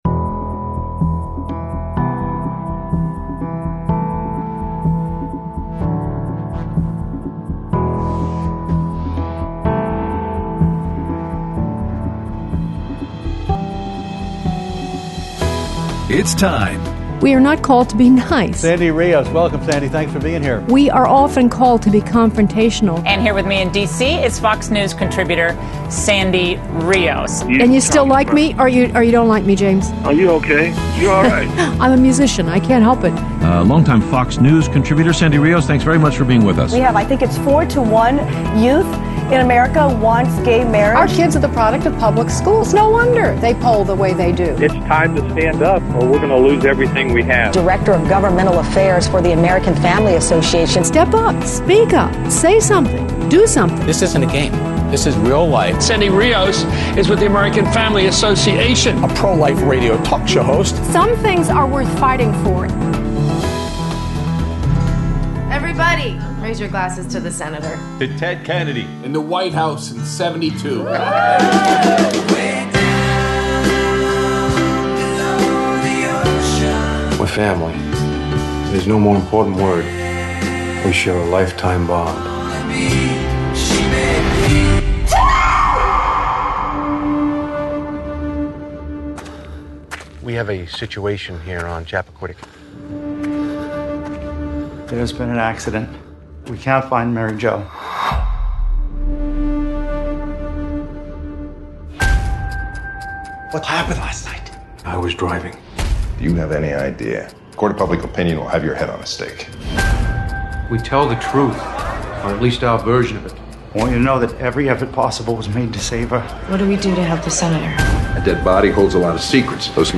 Best Of: Interview with Mark Ciardi on His New Movie, Chappaquiddick